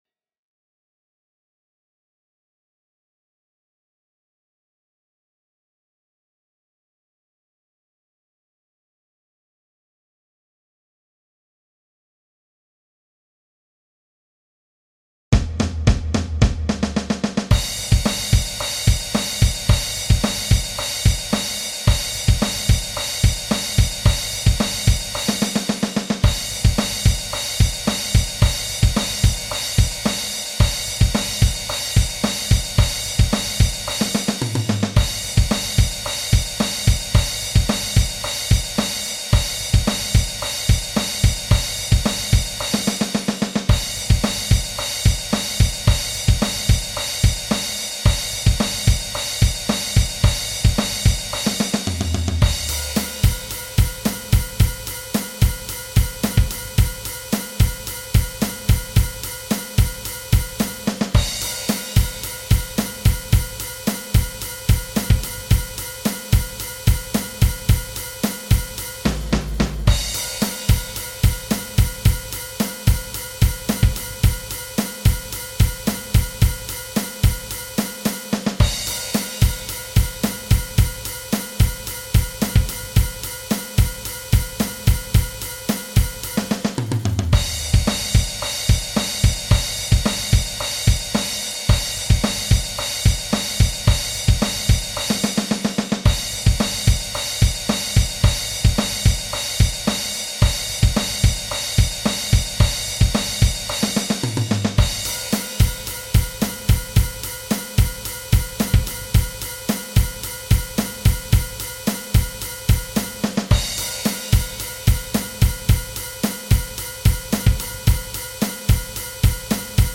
Du bist Schlagzeug Anfänger und willst deinen ersten Rocksong spielen?
Hier gibt es die kompletten Noten als pdf-Datei und ein Hörbeispiel als mp3-Datei: